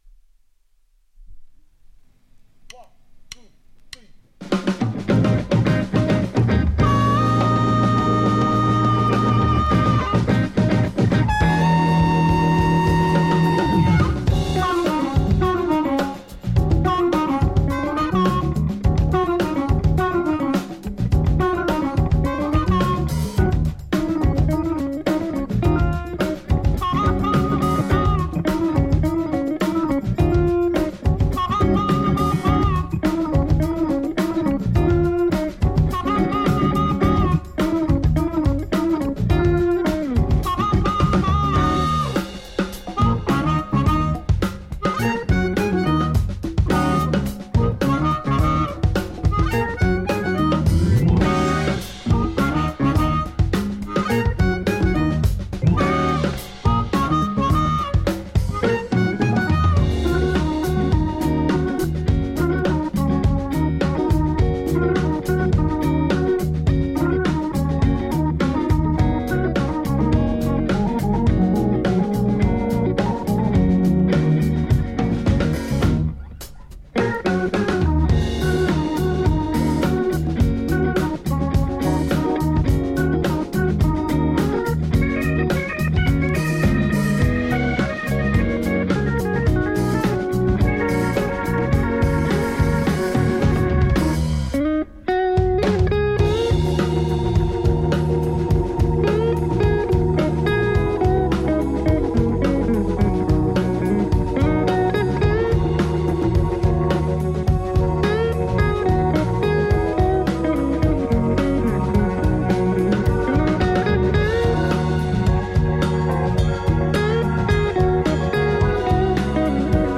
ジャンル(スタイル) JAPANESE SOUL / FUNK / JAZZ